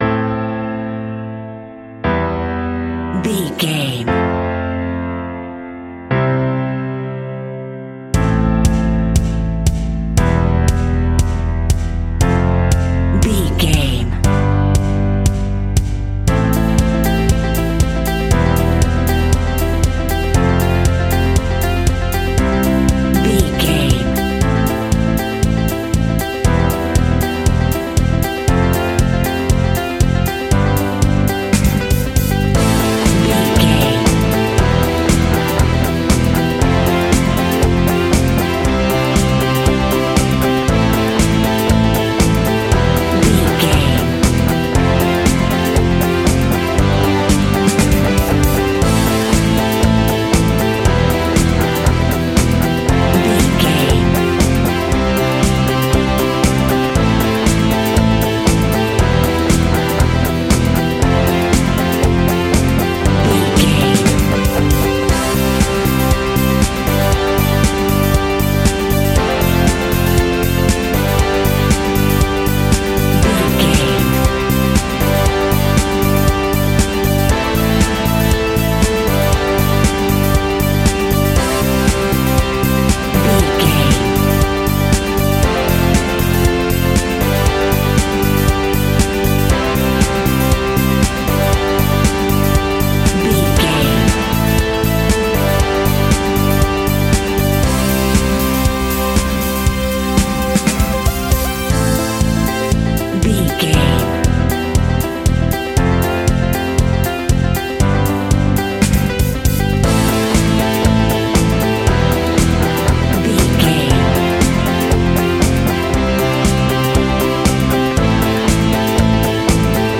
Ionian/Major
melancholic
energetic
smooth
uplifting
piano
electric guitar
bass guitar
drums
indie pop
organ